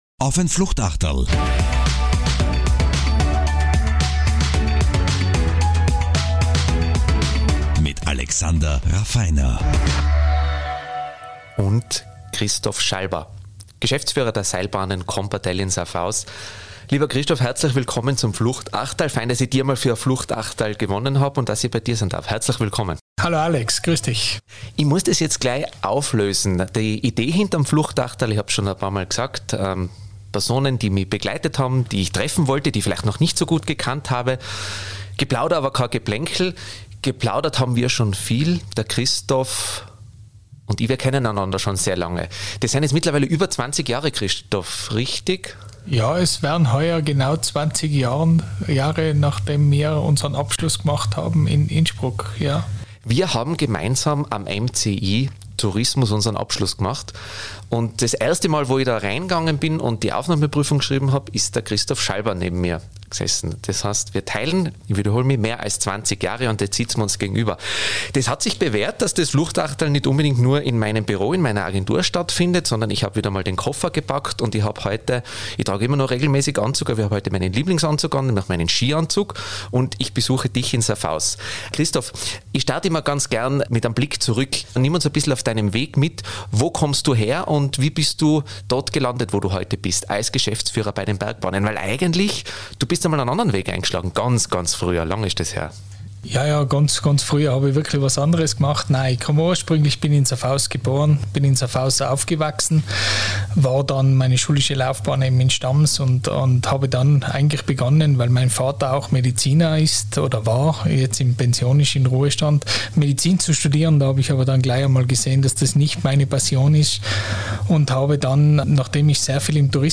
Ein Blick hinter die Kulissen und aktuelle Themen aufarbeiten. Ein Gespräch mit Menschen, nicht ihren Funktionen.
Es ist „unplugged“. Geplauder, aber kein Geplänkel.